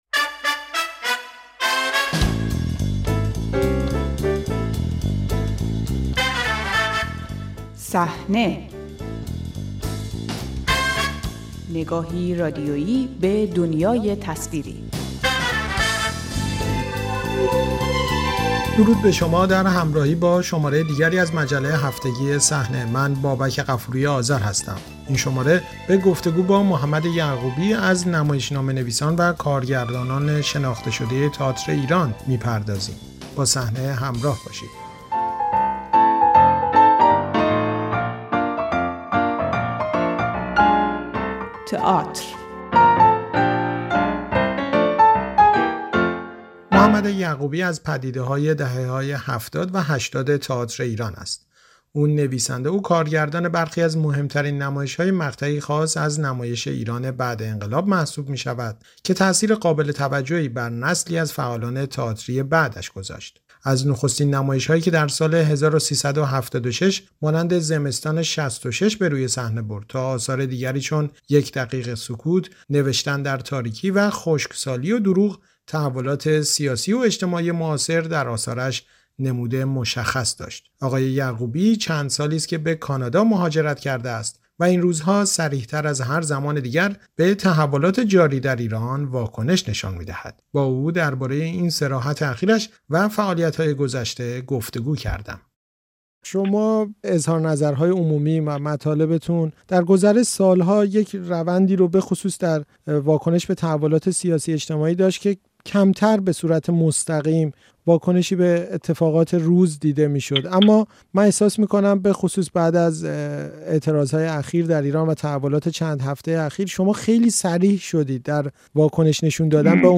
گفت‌وگو با محمد یعقوبی، کارگردان شناخته‌شده مقیم کانادا